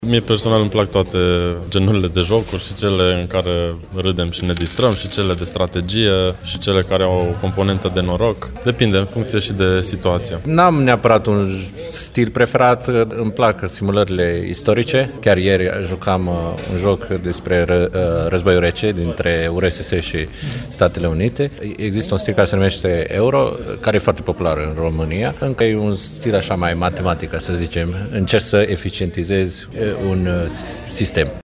Pasionaţii acestui domeniu spun că alegerea tipului de joc depinde şi de starea de moment pe care o au: